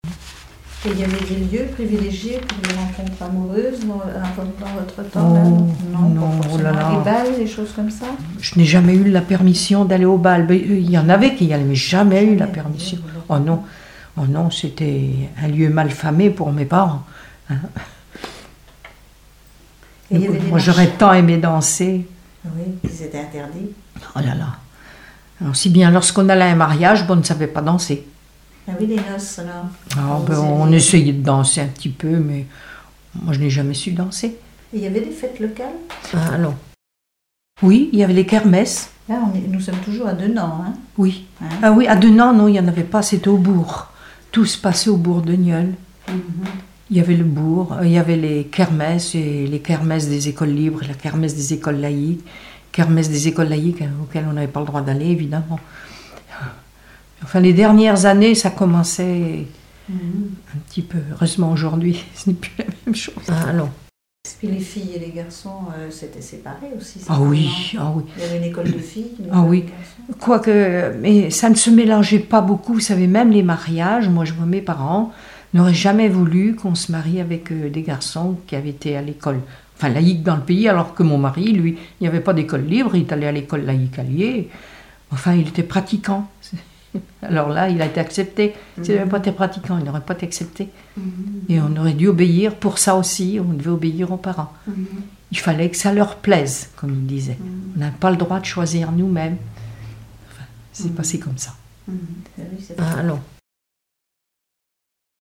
témoignages
Catégorie Témoignage